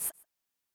okItsOngirls4.ogg